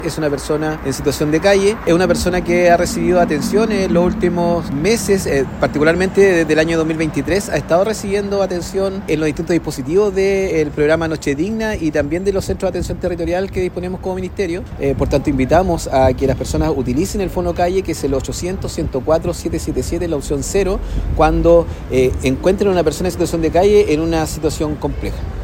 Hedson Díaz, seremi de Desarrollo Social, confirmó que que esta persona vive en situación de calle y que desde 2023 ha estado recibiendo atención en los distintos dispositivos como el programa Noche Digna.